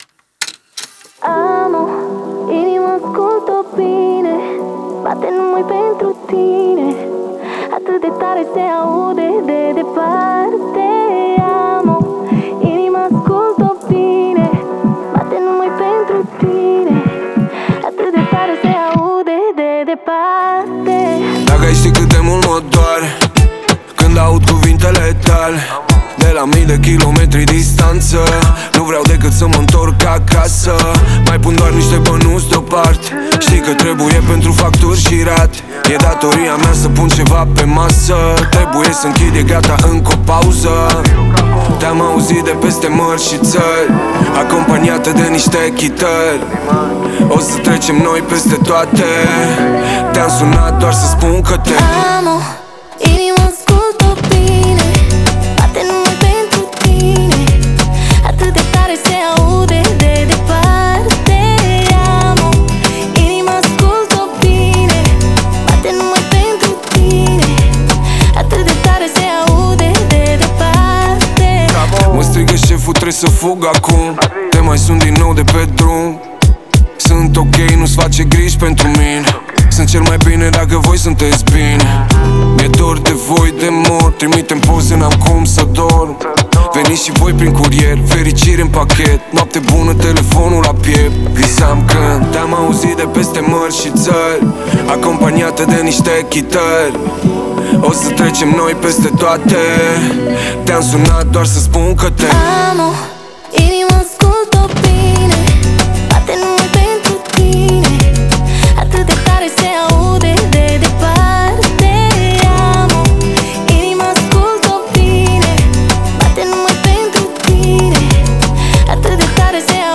мелодичные вокалы и ритмичные биты